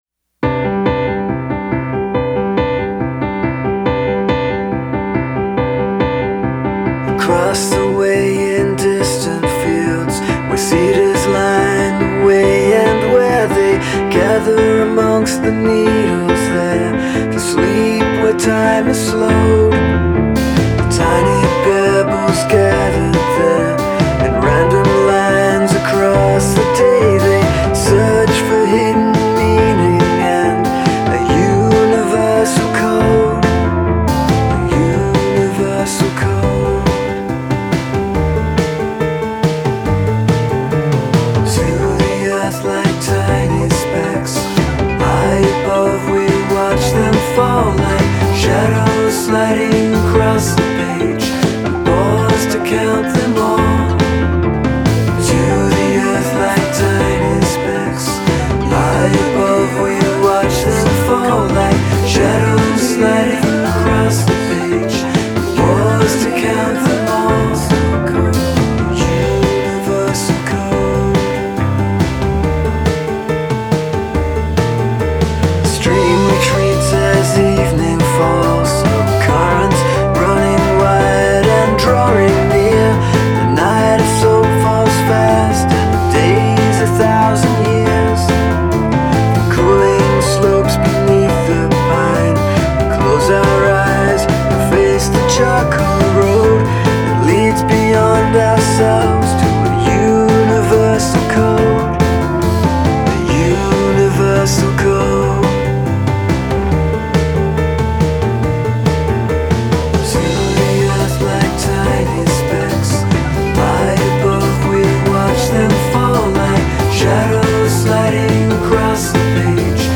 plaintive but upbeat, piano-driven
Piano-based rock music can have that effect on me, I think.